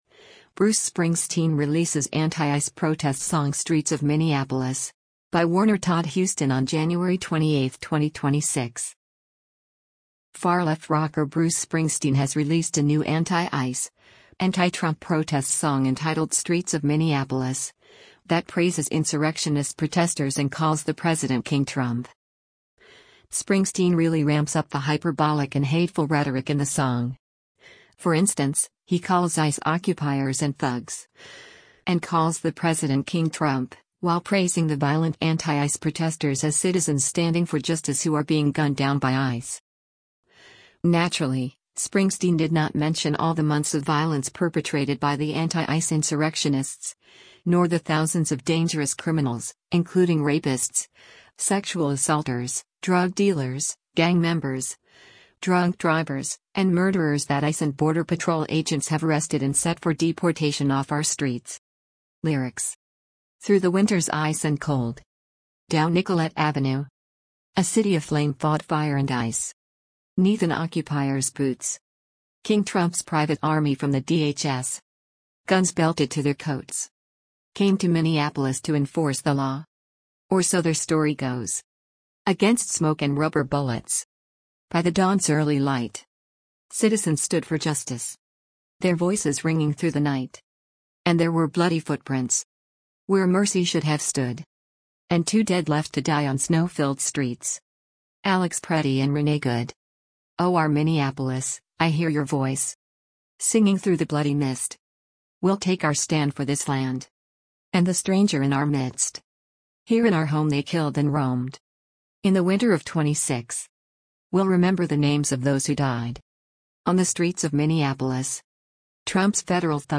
Far-left rocker